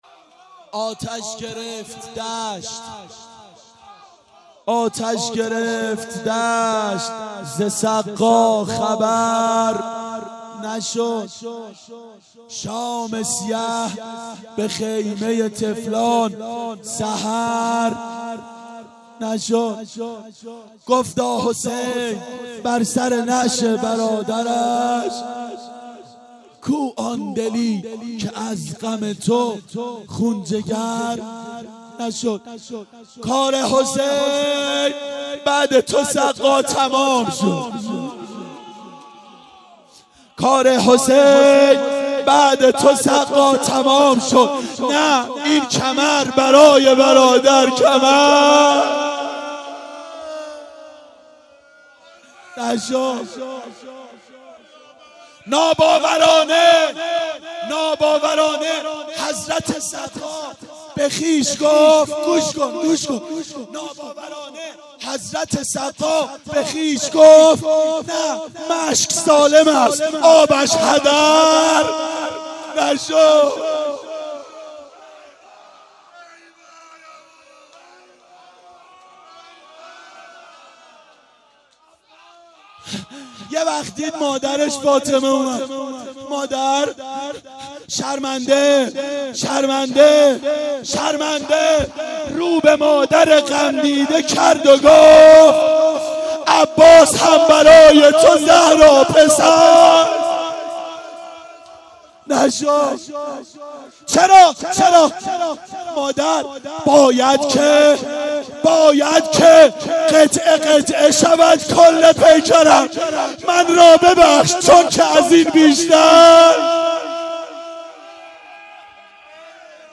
روضه حضرت عباس علیه السلام و شب عاشورا
شب عاشورا 1391 هیئت عاشقان اباالفضل علیه السلام
07-روضه-حضرت-عباس-ع-و-شب-عاشورا.mp3